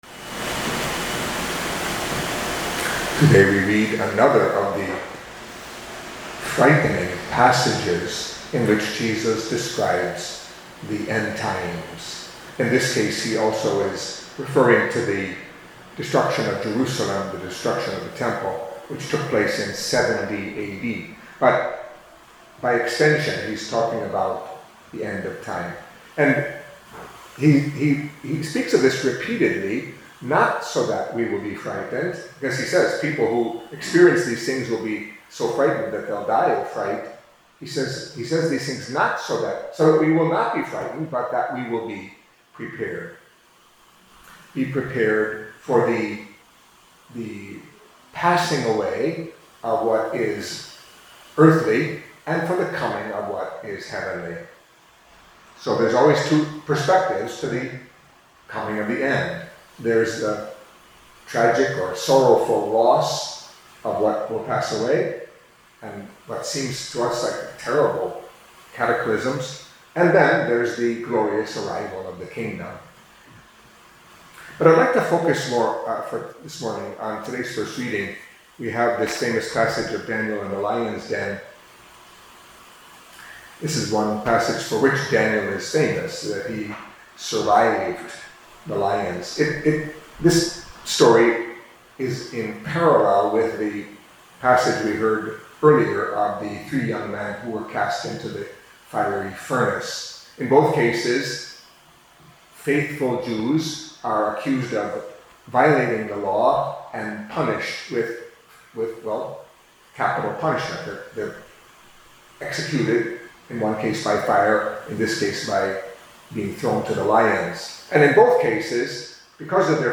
Catholic Mass homily for Thursday of the Thirty-Fourth Week in Ordinary Time